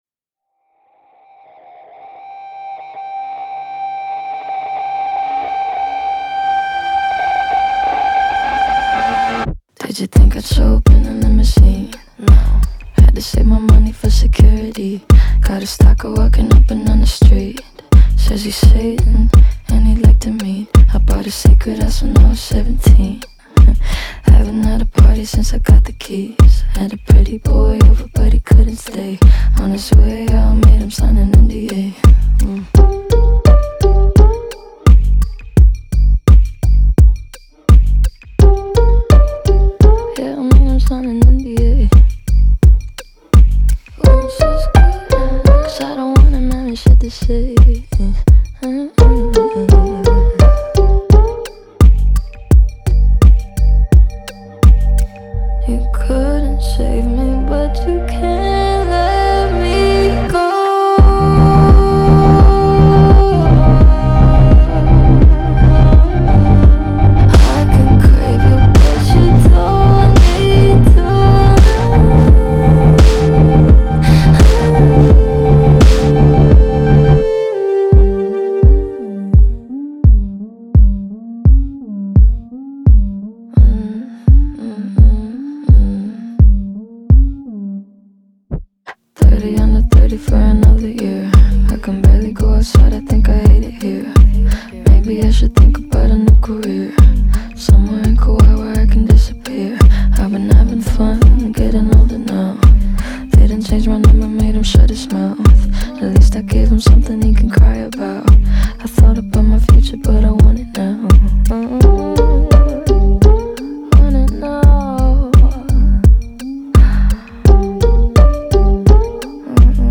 в жанре альтернативного попа